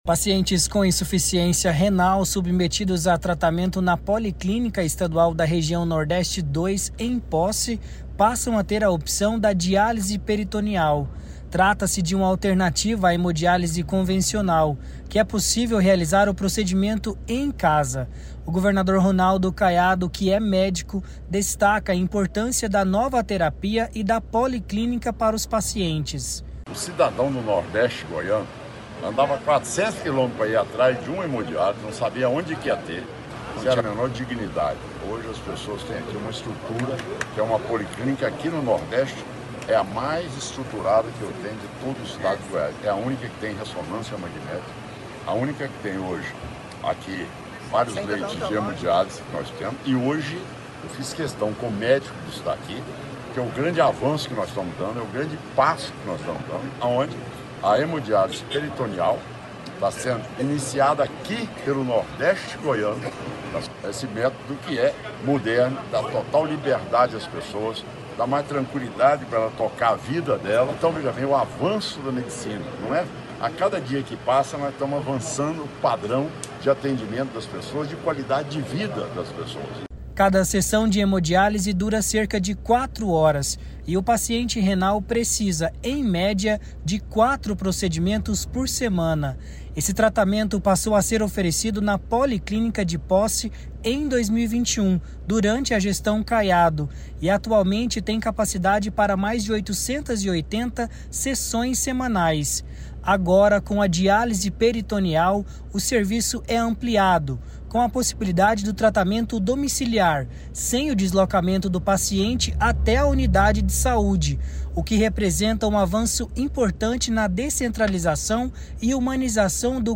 Governador comenta sobre a regionalização da Saúde em Goiás e lembra que o morador de Nordeste goiano não precisa mais realizar um deslocamento de mais de 400 quilômetros por uma hemodiálise no Estado.